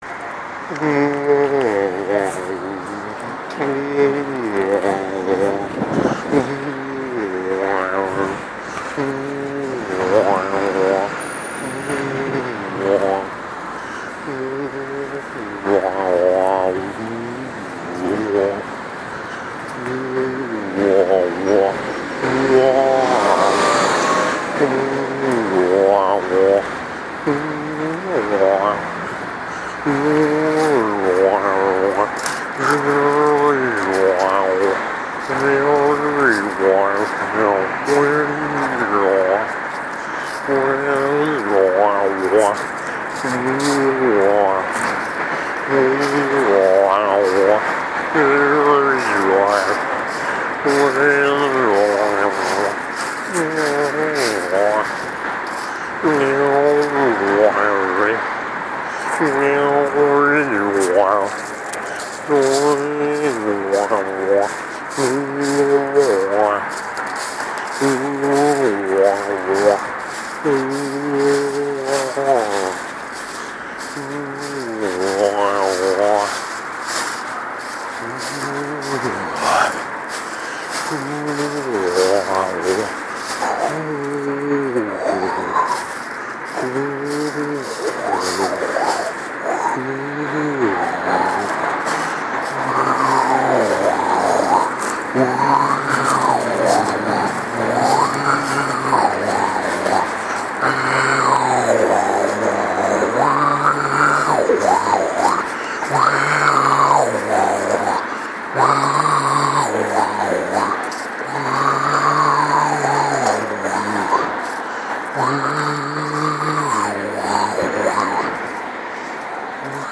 Trace of a shadow - vocal improv